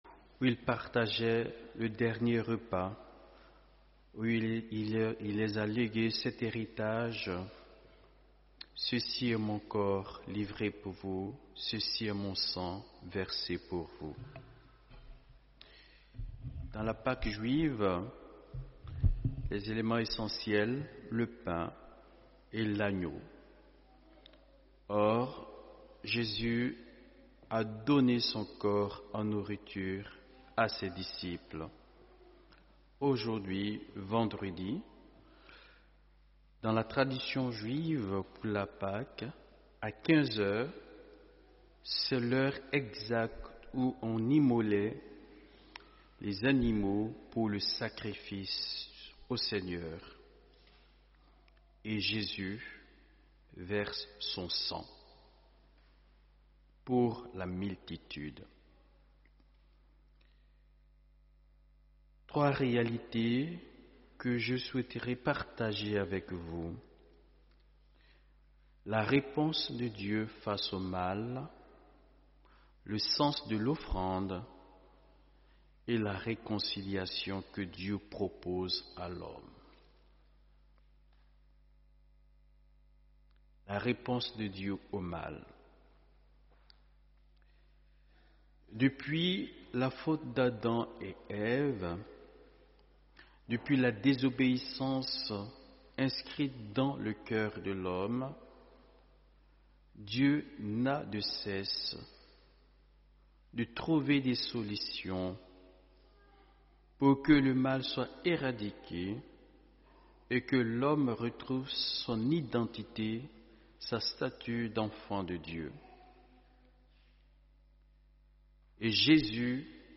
OFFICE DE LA PASSION Vendredi-saint 18 avril 2025